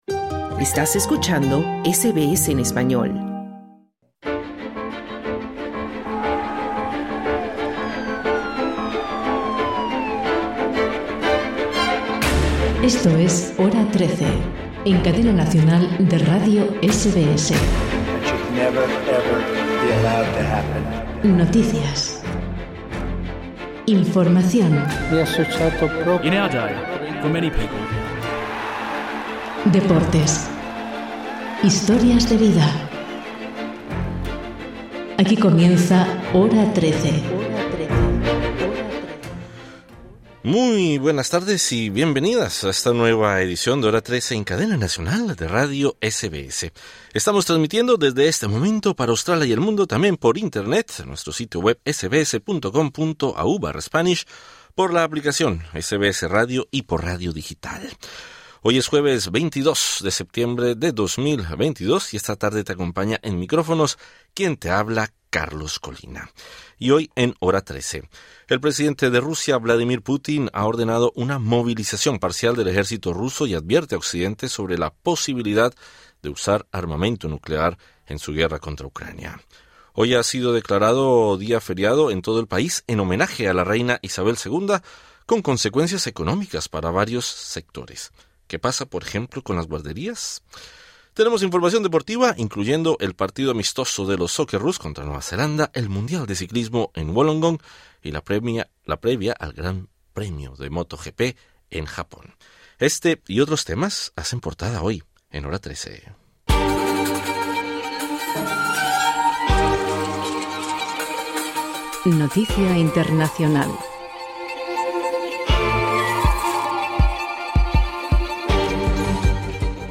Programa en español de SBS Radio.